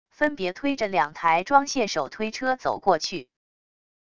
分别推着两台装卸手推车走过去wav音频